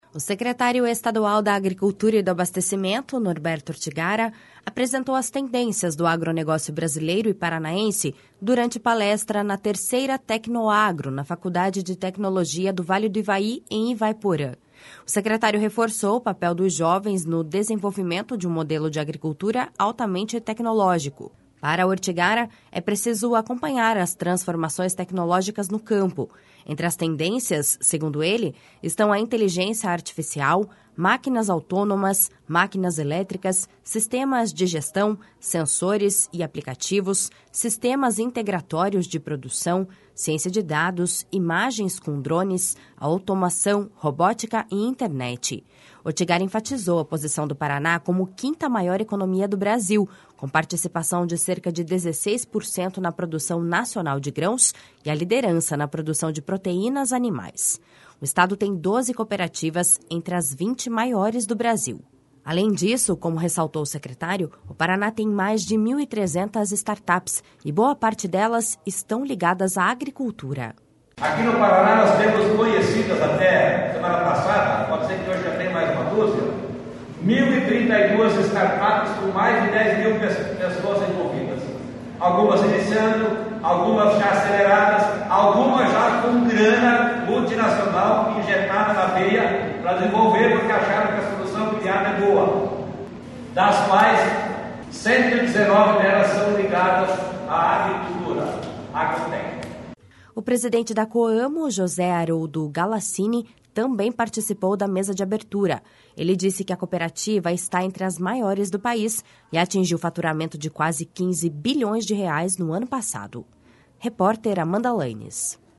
O secretário estadual da Agricultura e do Abastecimento, Norberto Ortigara, apresentou as tendências do agronegócio brasileiro e paranaense, durante palestra na 3ª Tecnoagro, na Faculdade de Tecnologia do Vale do Ivaí, em Ivaiporã. O secretário reforçou o papel dos jovens no desenvolvimento de um modelo de agricultura altamente tecnológico.